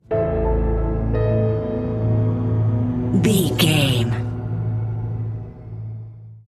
Thriller
Aeolian/Minor
Slow
piano
synthesiser
electric piano
ominous
dark
suspense
haunting
creepy